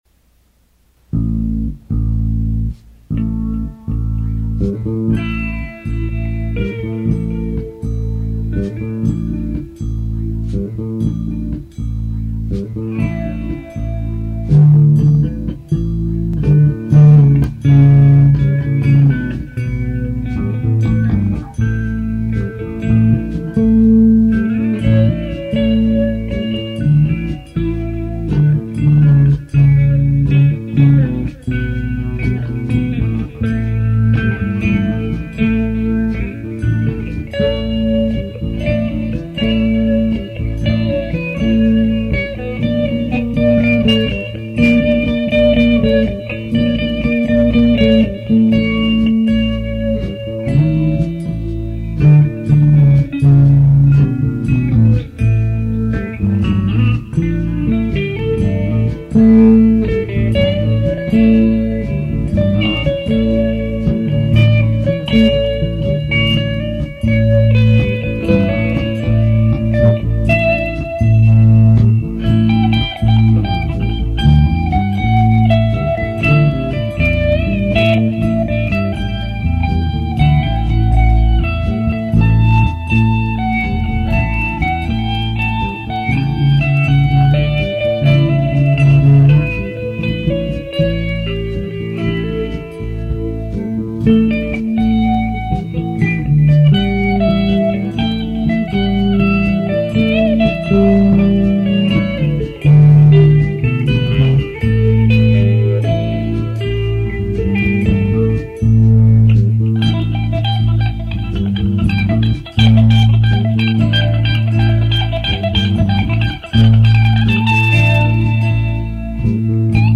This is an instrumental which started on the bass.
It has a pedestrian beat to it. Very easy going… and reminds me of Fleetwood Mac’s Albatros song.
And the ending is funny!